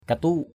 /ka-tu:ʔ/ (đg.) địt, rắm, đánh rắm = péter. ndom yau asaih katuk _Q’ y~@ a=sH kt~K nói như ngựa đánh rắm. say like horse farts.